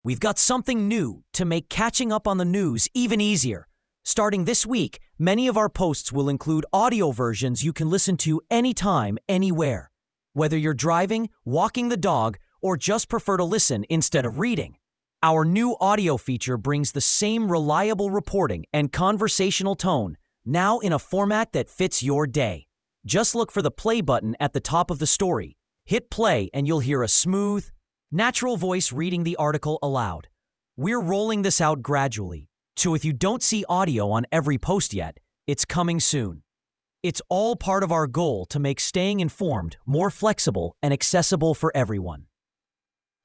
Hit play, and you’ll hear a smooth, natural voice reading the article aloud.